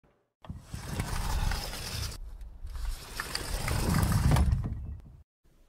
دانلود صدای باز و بستن در اتوماتیک یا کشویی از ساعد نیوز با لینک مستقیم و کیفیت بالا
جلوه های صوتی